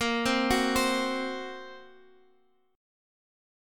A#sus2#5 chord